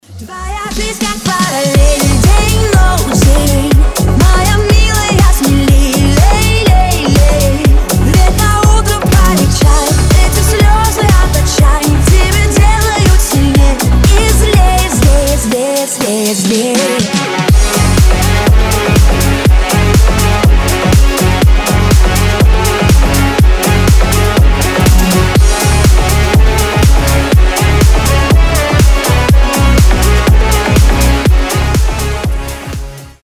• Качество: 320, Stereo
Club House